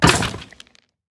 Minecraft Version Minecraft Version latest Latest Release | Latest Snapshot latest / assets / minecraft / sounds / mob / wither_skeleton / hurt1.ogg Compare With Compare With Latest Release | Latest Snapshot
hurt1.ogg